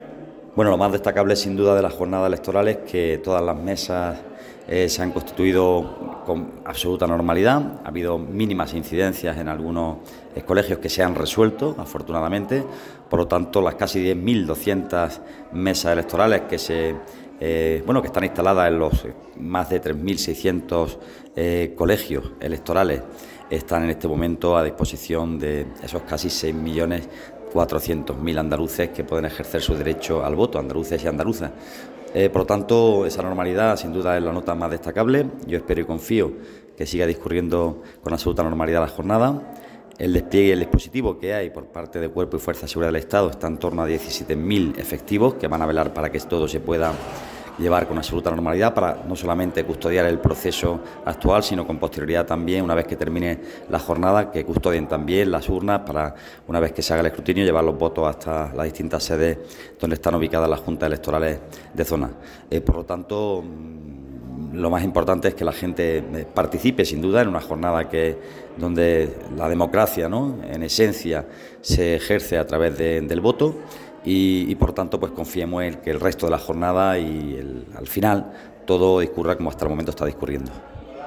A destacar la presencia en Baza del delegado del Gobierno en Andalucía, el bastetano Pedro Fernández Peñalver, quien acudió a ejercer el derecho al voto en su ciudad natal y nos dejaba estas impresiones:
El delegado del Gobierno en Andalucía votó en su ciudad de nacimiento